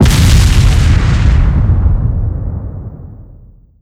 Explo_Large.wav